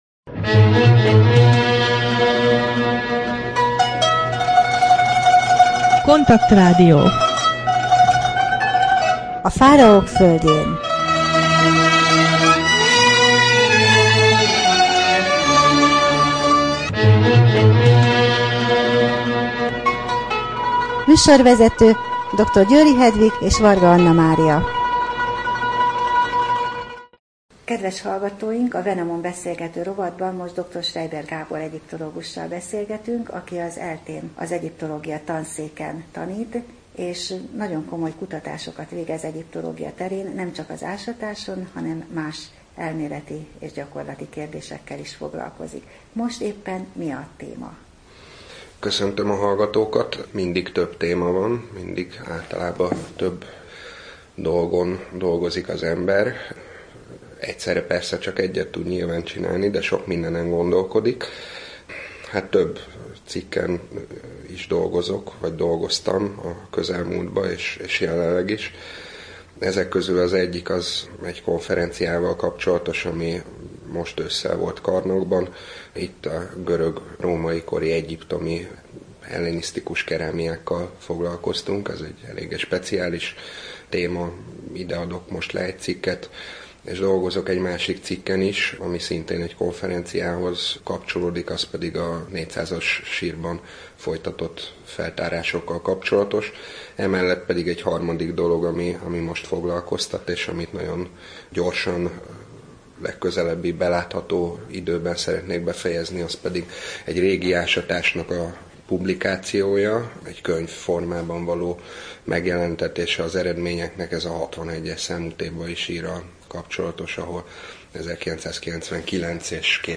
Wenamon rovat: Beszélgetés